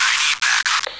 radio_backup.wav